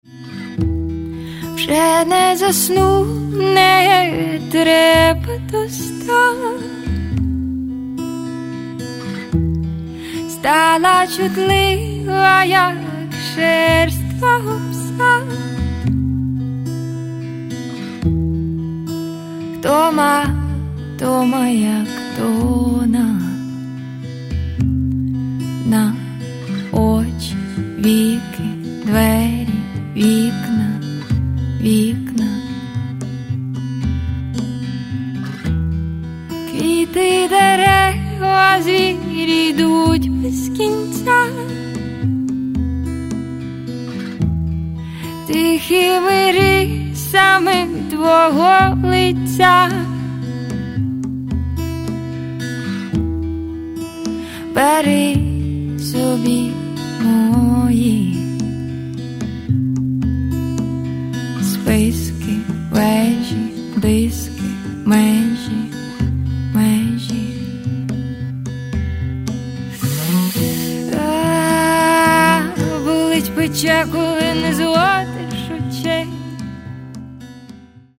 Catalogue -> Rock & Alternative -> Lyrical Underground
guitar, vocals, percussion